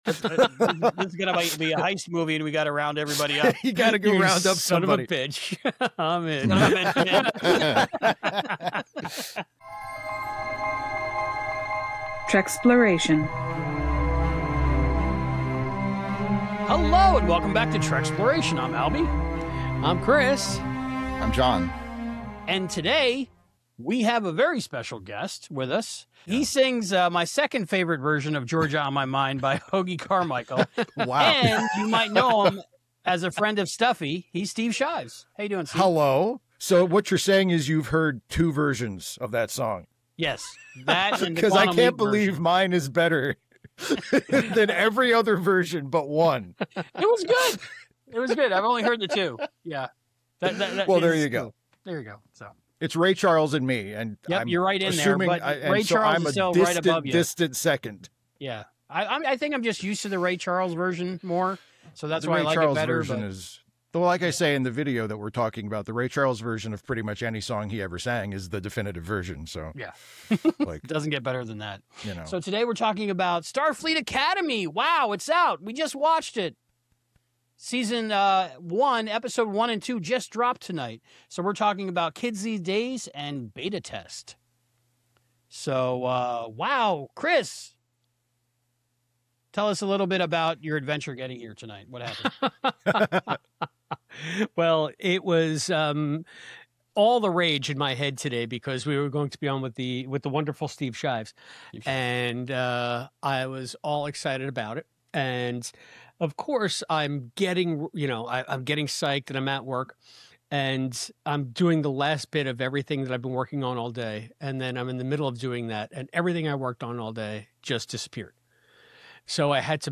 Review